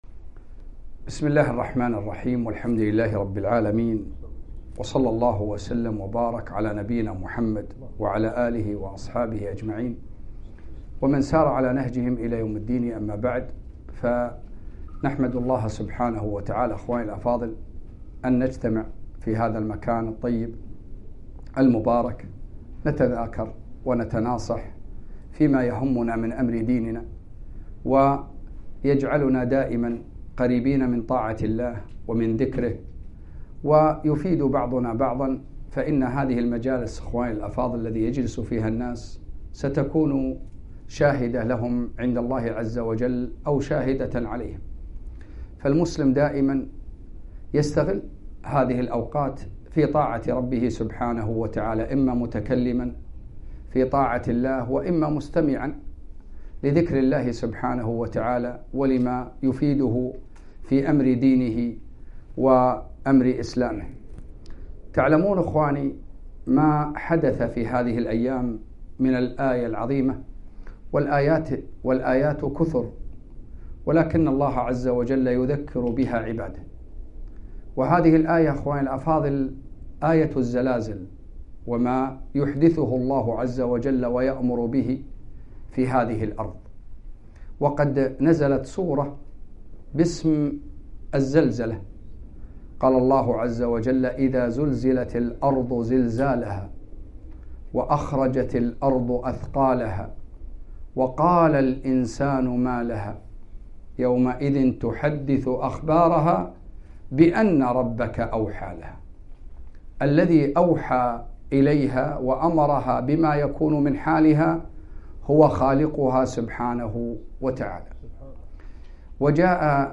كلمة - الزلازل عظة وعبرة